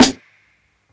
dsaf-snr (1).wav